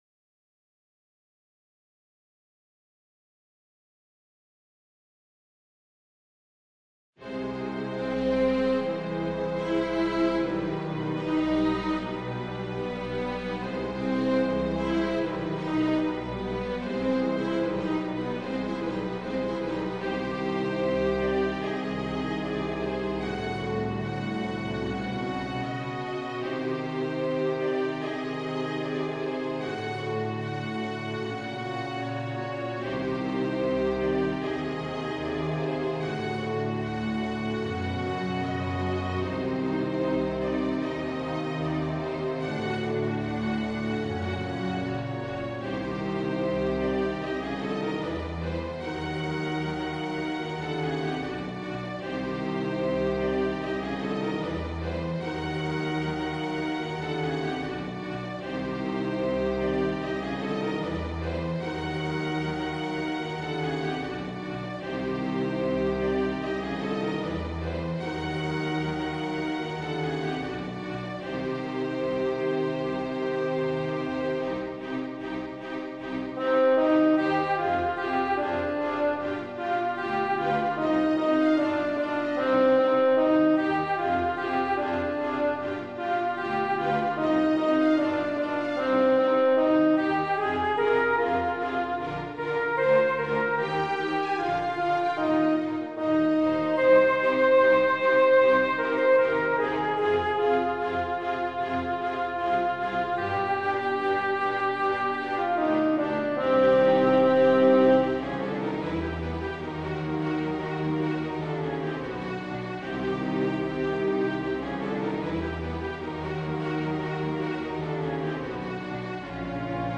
It is a slightly darker version .....